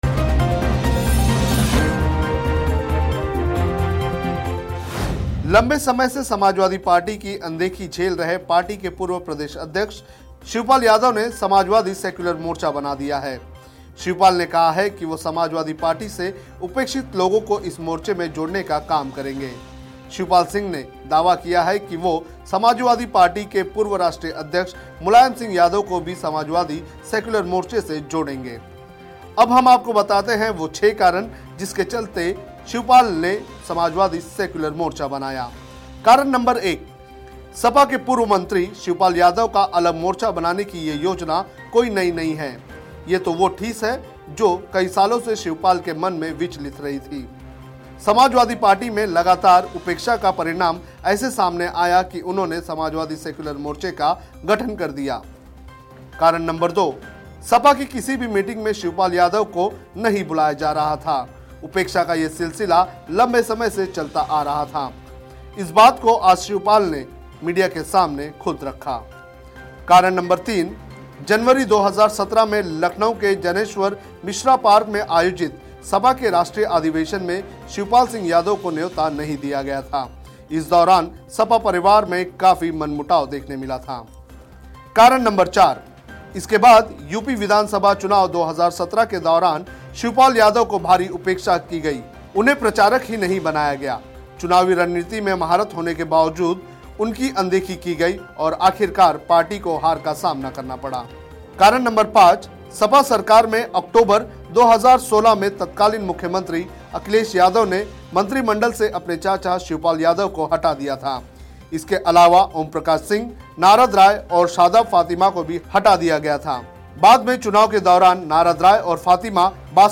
न्यूज़ रिपोर्ट - News Report Hindi / 6 कारण जिस लिए शिवपाल यादव ने बनाया समाजवादी सेक्युलर मोर्चा